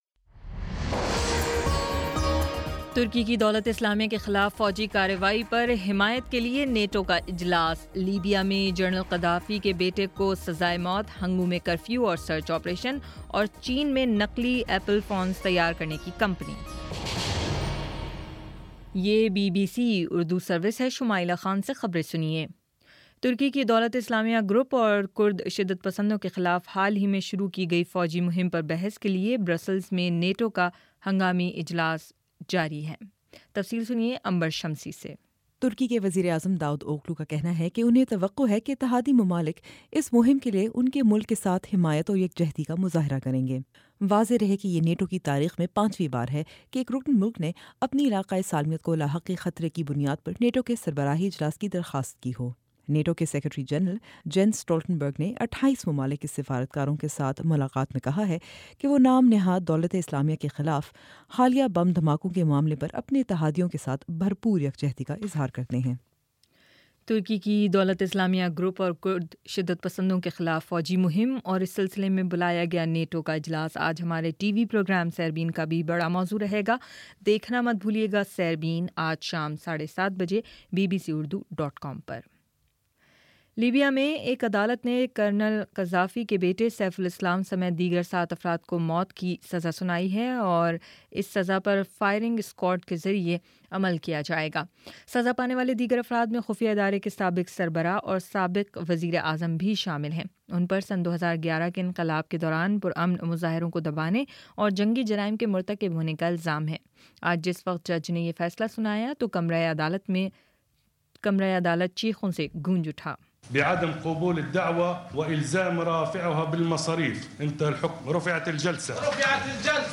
جولائی 28: شام چھ بجے کا نیوز بُلیٹن